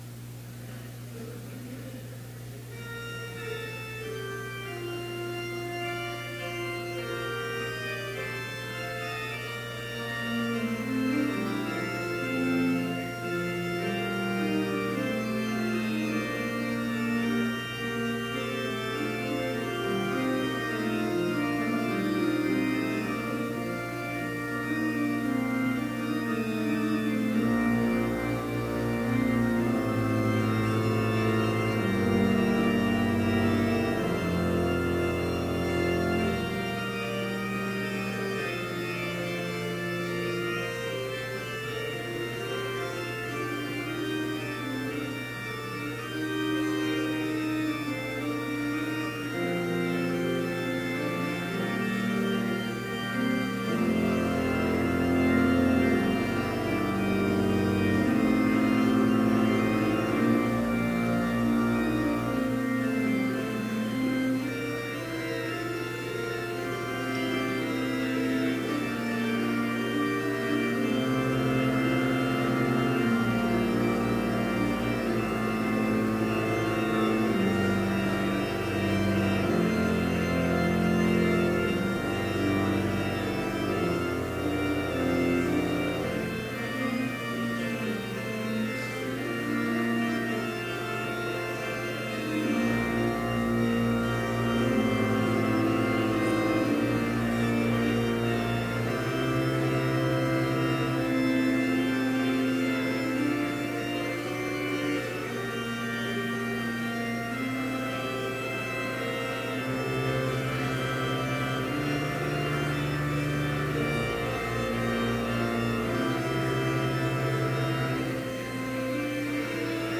Complete service audio for Chapel - September 27, 2018